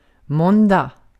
Ääntäminen
Ääntäminen Tuntematon aksentti: IPA: /ˈmɔndɑːɡ/ IPA: /ˈmɔnda/ Lyhenteet ja supistumat mån Haettu sana löytyi näillä lähdekielillä: ruotsi Käännös Ääninäyte Substantiivit 1.